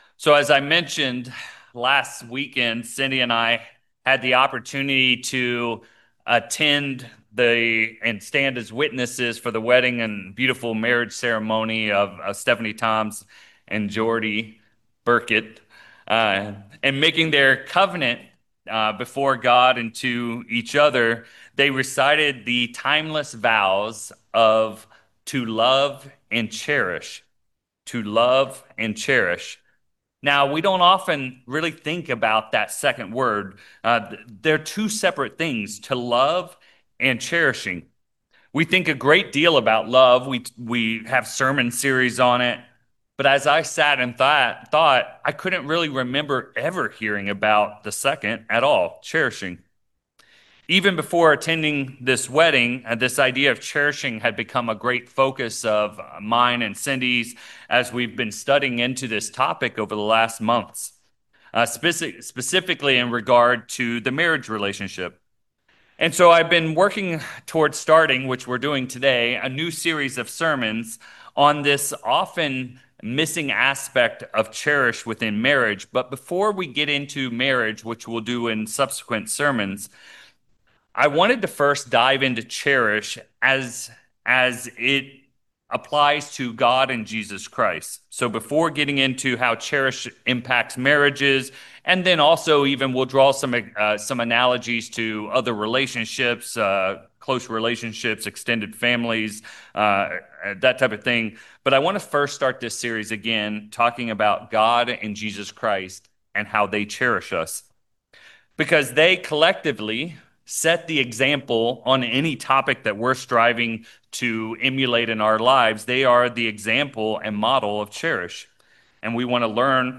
In this sermon series, we explore how cherishing is a vital — yet often missing — element in marriage. In this first message, however, we begin by looking at the incredible ways God the Father and Jesus Christ cherish us. Together, we will explore seven powerful ways God cherishes His people.